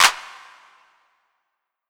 Claps
JJClap (4).wav